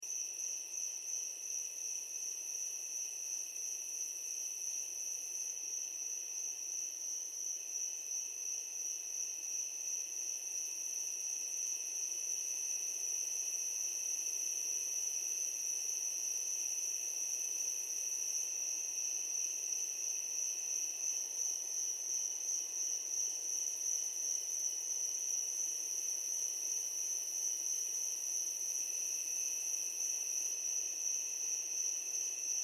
دانلود آهنگ شب 6 از افکت صوتی طبیعت و محیط
دانلود صدای شب 6 از ساعد نیوز با لینک مستقیم و کیفیت بالا
جلوه های صوتی